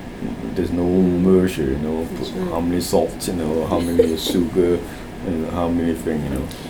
S1 = Taiwanese female S2 = Hong Kong male Context: S2 is talking about how creative his mother was in doing the cooking, especially in the absence of recipe books or other guidance about how to do the cooking.
Discussion : S2 has a mid central vowel [ɜ:] rather than [e] in the first syllable of measure , and as a result, S1 was not able to suggest any candidate for the word.